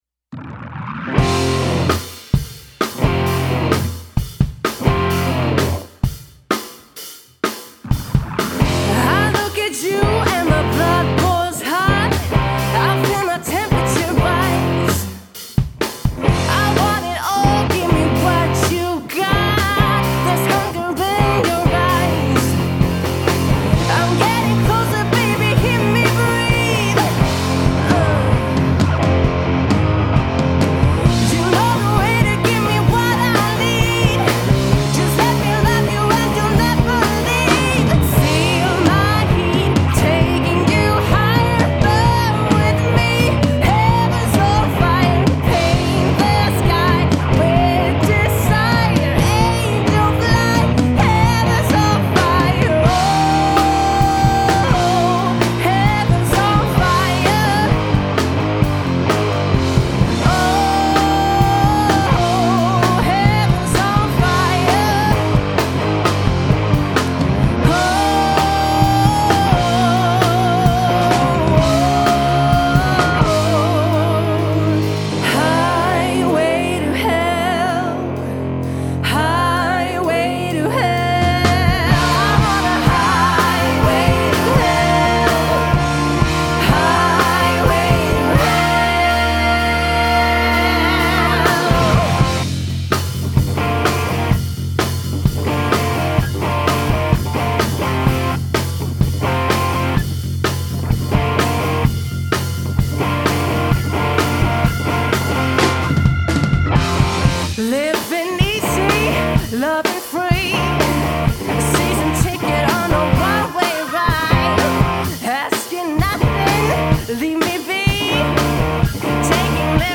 Sång
Piano/keys
Gitarr
Trummor